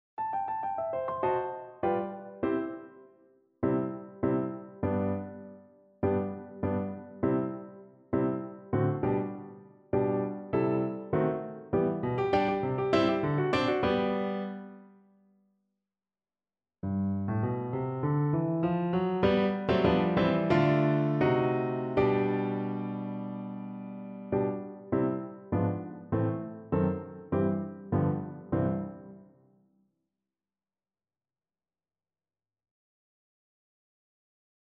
2/4 (View more 2/4 Music)
Quick and Light
Classical (View more Classical Flute Music)